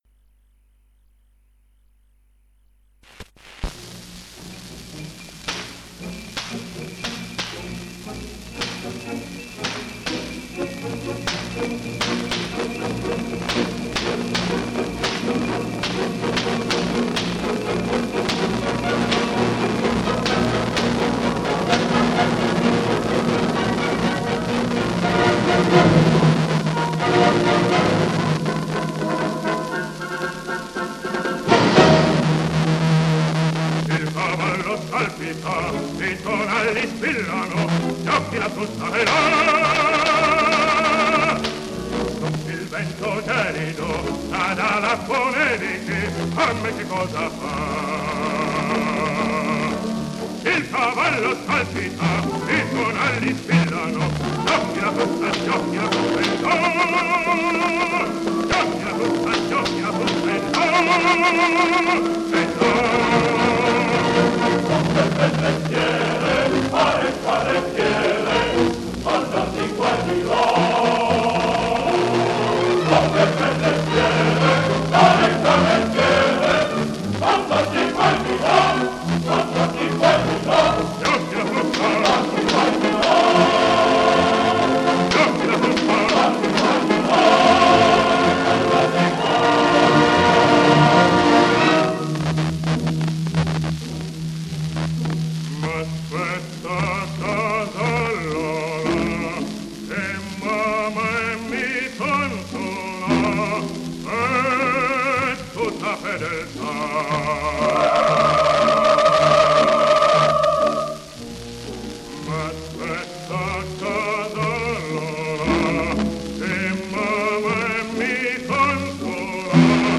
undici dischi a 78 giri del 1943 in cui è registrata la Cavalleria Rusticana
La registrazione è avvenuta a Milano sotto l’egida della celeberrima casa discografica LA VOCE DEL PADRONE.
Orchestra e Coro del Teatro alla Scala.
N 7.- Il cavallo scalpita... Gino Bechi bar. e Coro         SCARICA